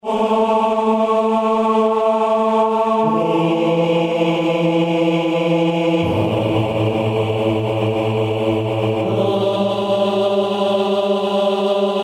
PS合唱团套装男声合唱团天时地利人和
Tag: 80 bpm Hip Hop Loops Vocal Loops 2.02 MB wav Key : Unknown